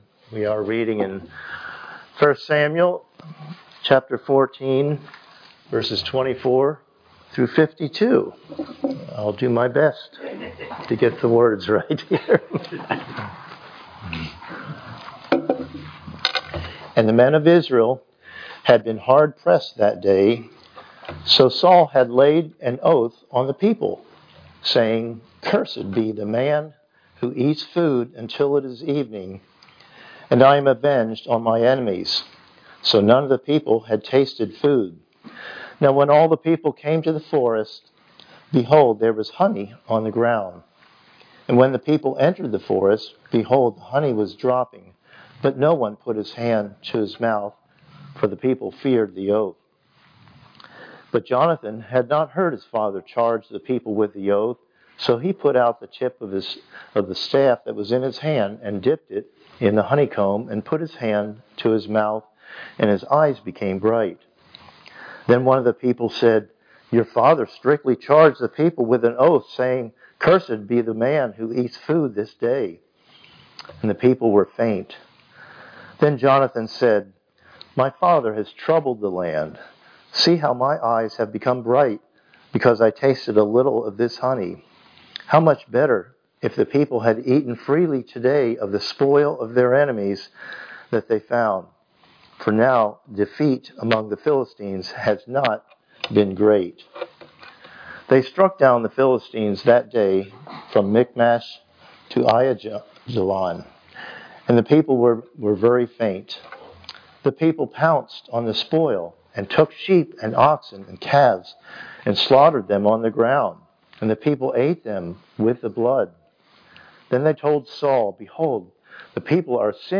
Passage: 1 Samuel 14:24-52 Service Type: Sunday Morning Worship